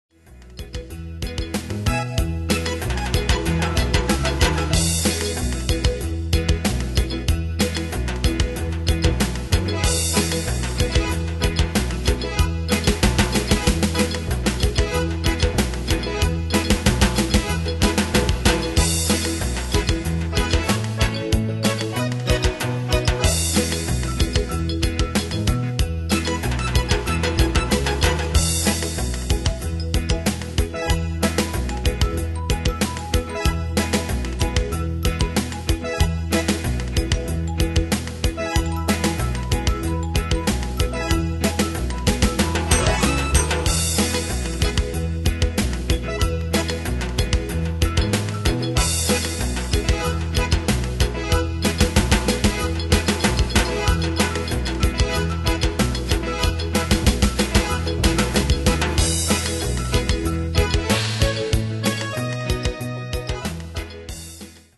Bandes et Trames Sonores Professionnelles
Pro Backing Tracks